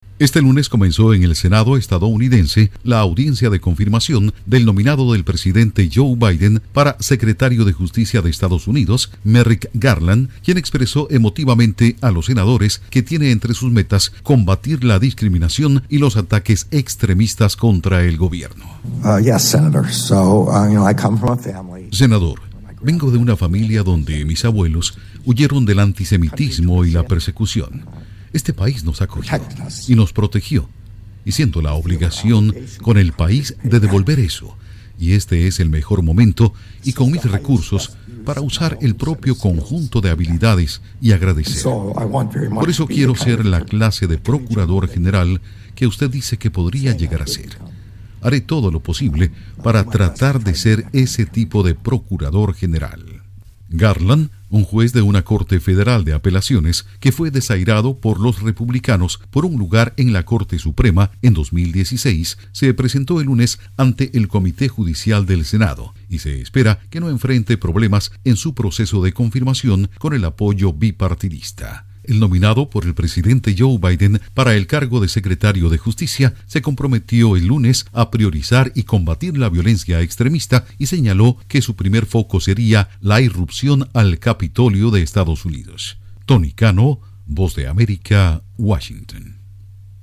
Comienzan audiencias en el Senado de Estados Unidos al nominado de Biden para secretario de Justicia. Informa desde la Voz de América en Washington